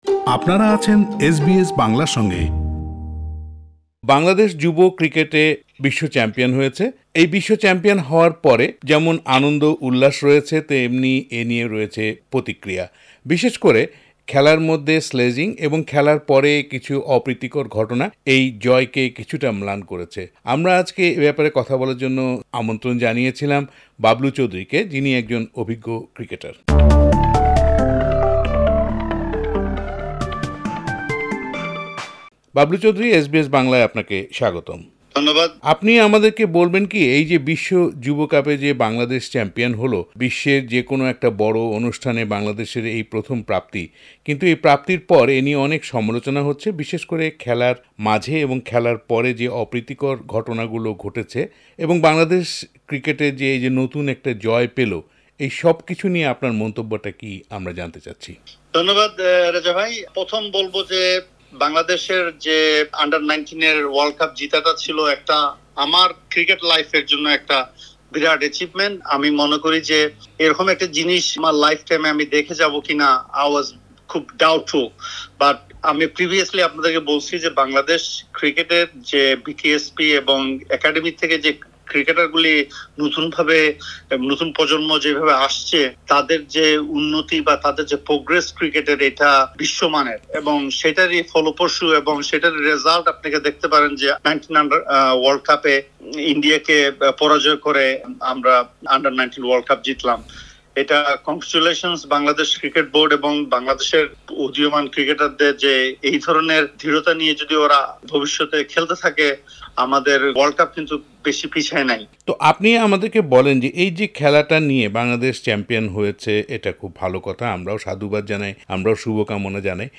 এসবিএস বাংলার সঙ্গে কথা বলেছেন।
সাক্ষাৎকারটি বাংলায় শুনতে উপরের অডিও প্লেয়ারটিতে ক্লিক করুন।